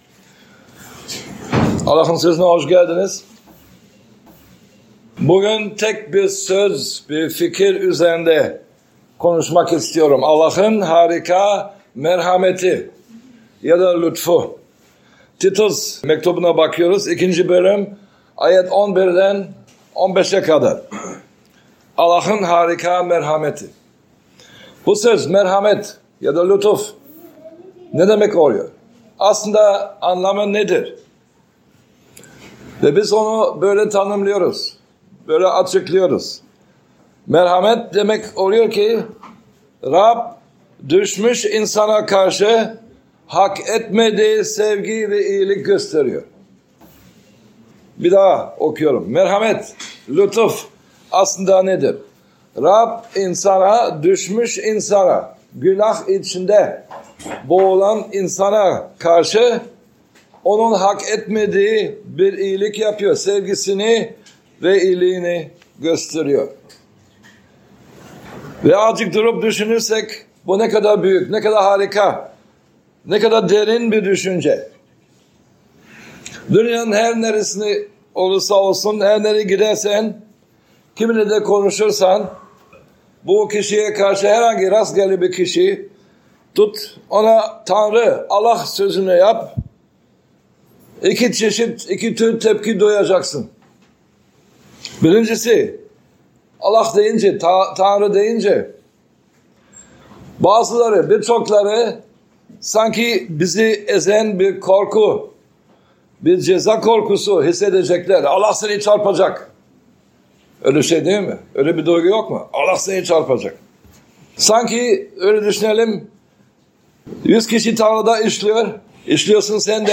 Vaazlar